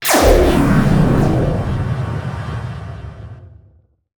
SPACE_WARP_Complex_04_stereo.wav